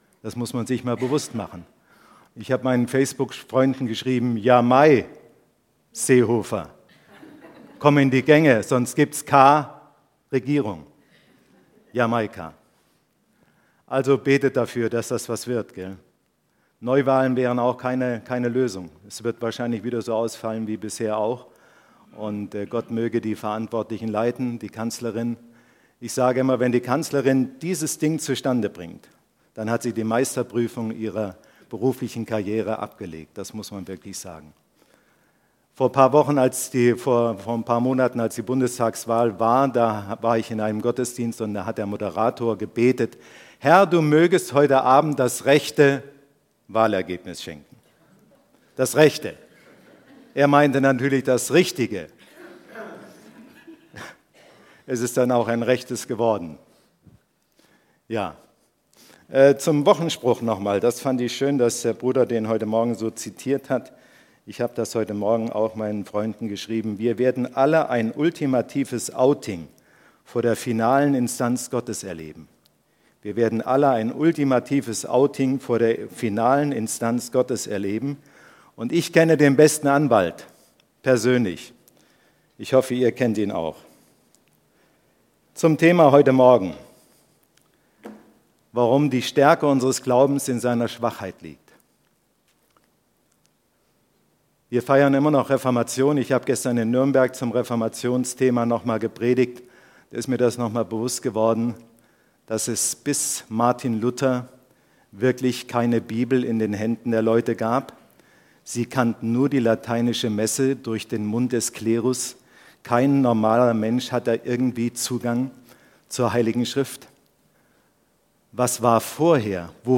Serie: Gastprediger